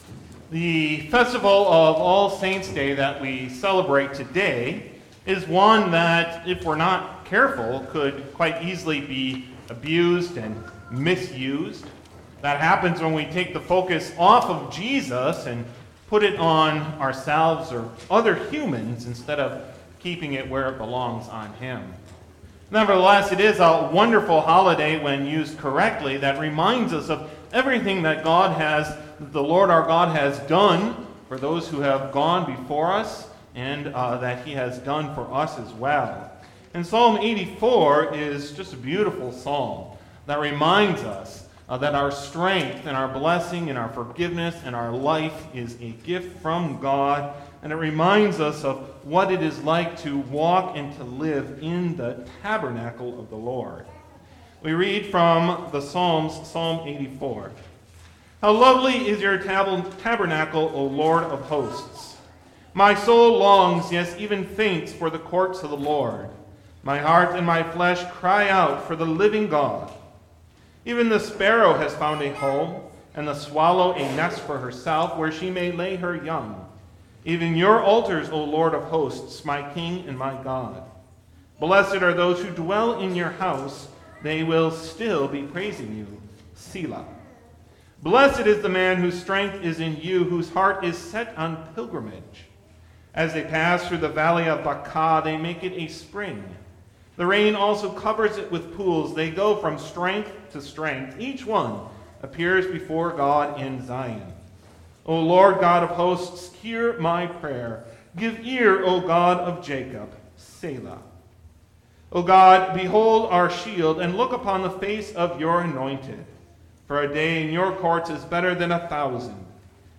Audio Sermon
Festival: All Saints Day